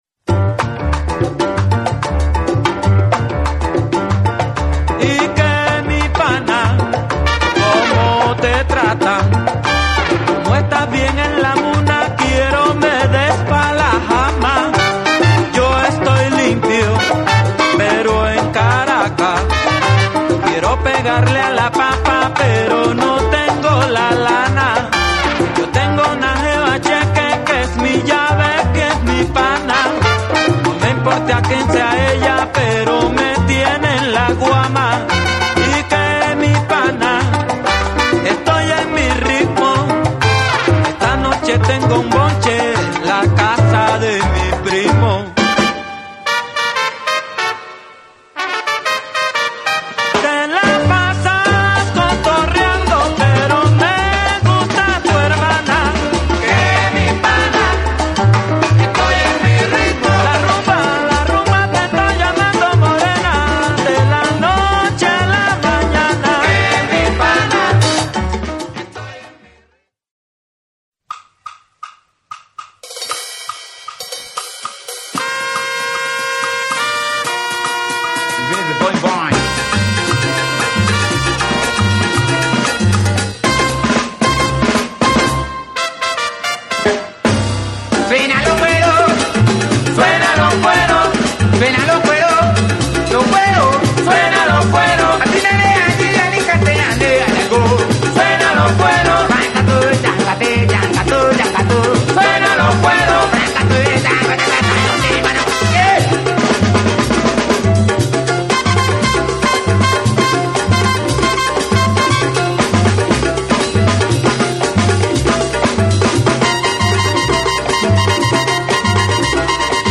キラー・チューンと呼ばれるスカと同じ匂いがするワイルド・ワイルド・サルサ。